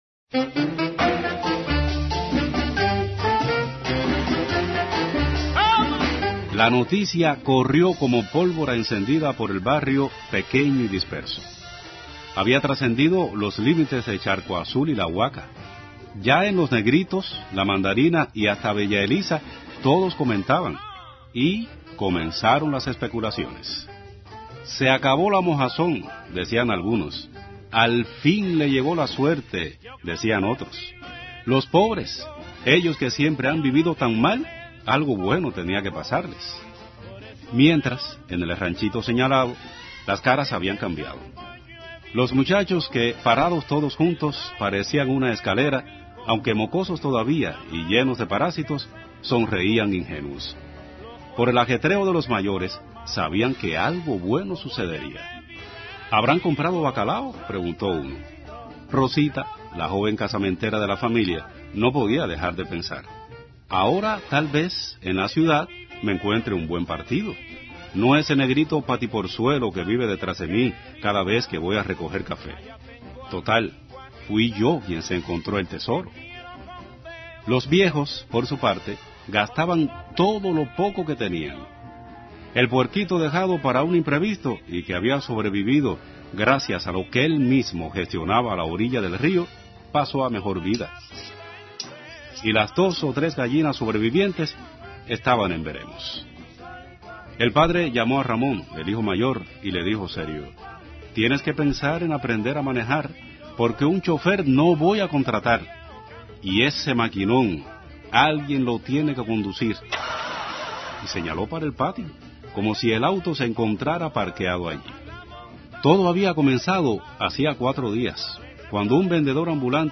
(PROGRAMA EN COMPETENCIA. FESTIVAL MUNICIPAL DE LA RADIO)